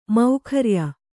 ♪ maukharya